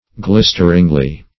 [1913 Webster] Glisteringly \Glis"ter*ing*ly\, adv.